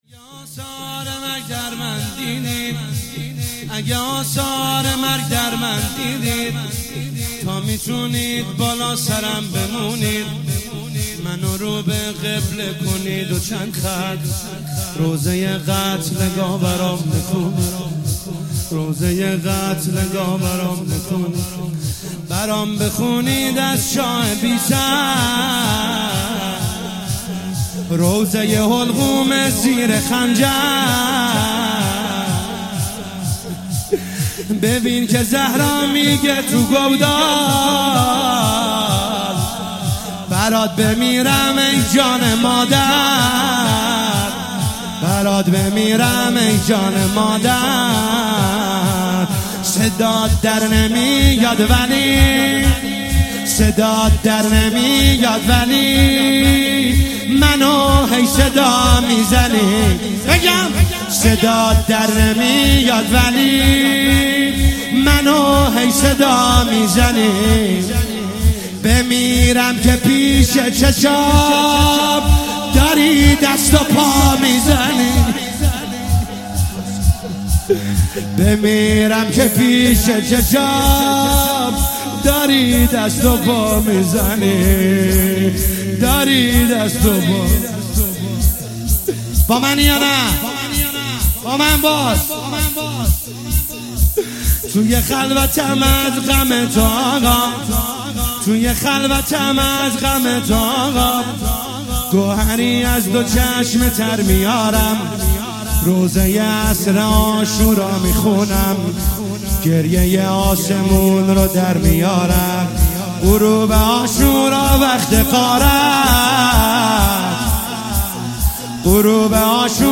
محرم1401شب اول -شورلطمه- اگه آثار مرگ